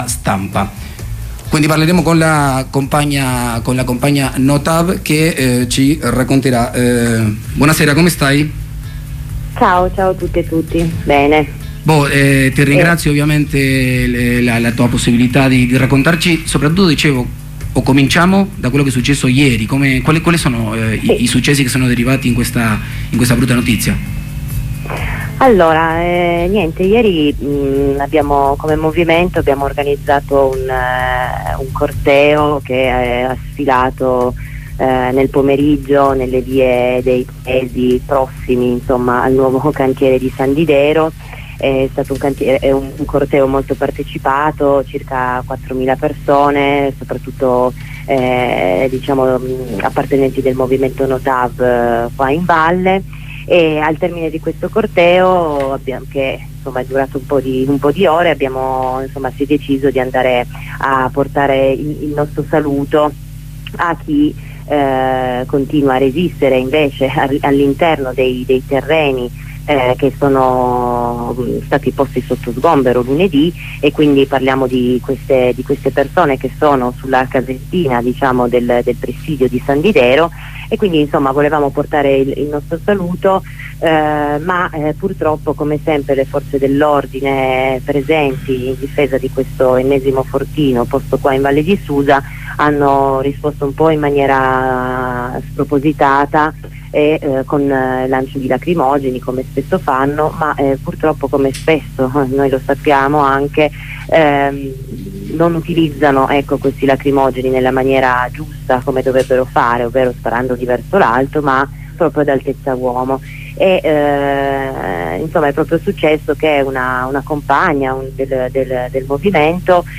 dalla Val Susa